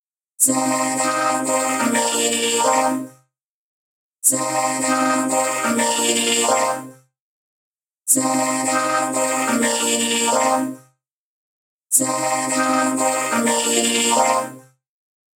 The vocoders settings get tweaked for clarity.
The new vocal in isolation.